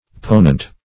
Ponent \Po"nent\, a.